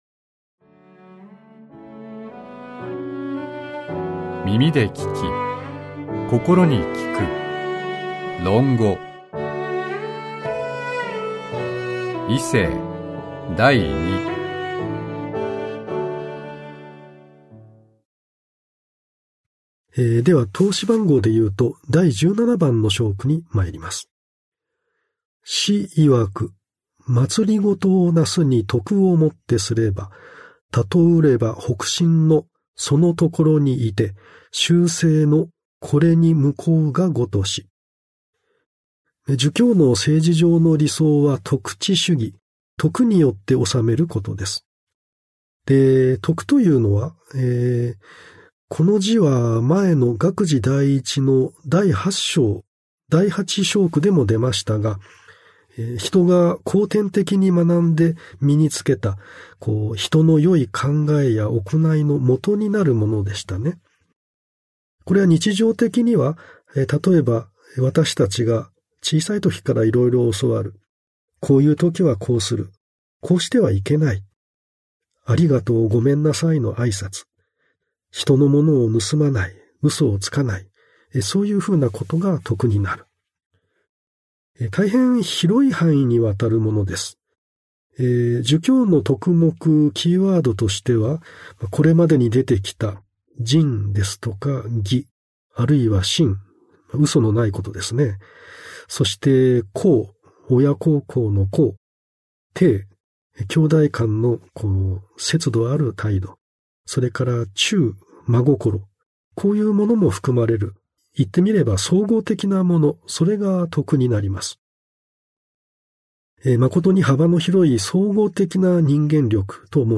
[オーディオブック] 耳で聴き、心に効く『論語』為政第二